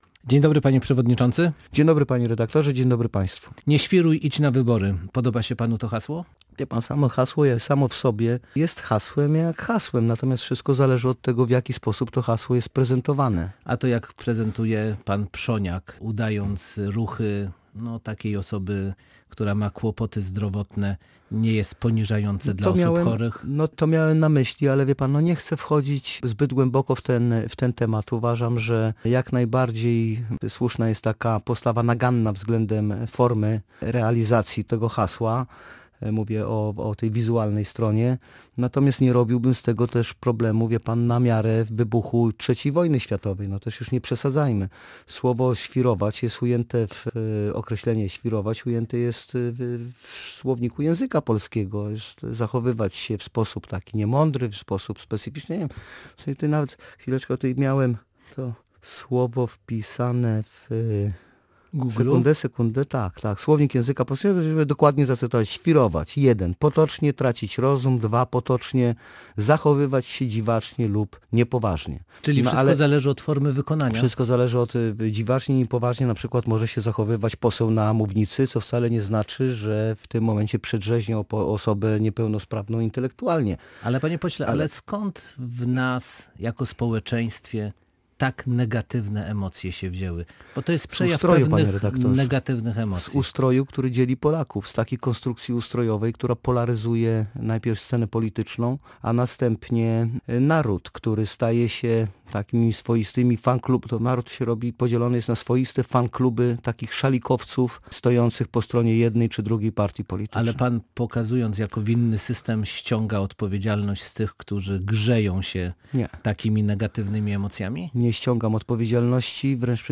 Na pytanie odpowiedział lider ugrupowania, który był gościem Radia Gdańsk.